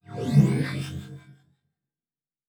Teleport 9_2.wav